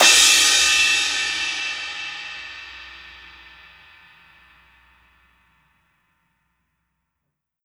Crashes & Cymbals
18inchmedthin-f.wav